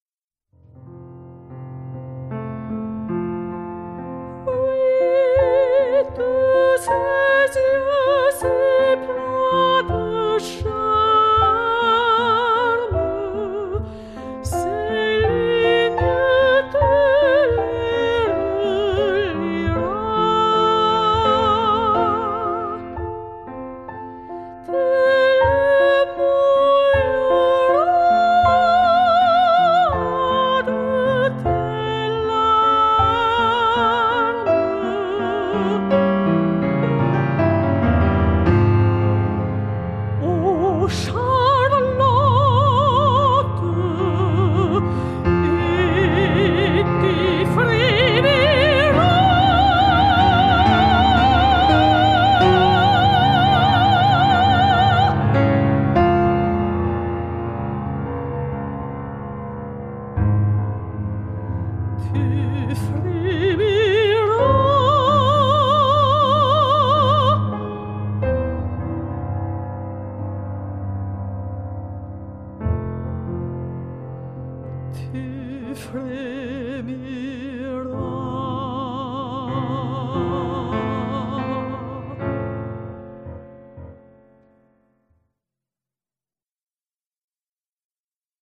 Mezzo Soprano
piano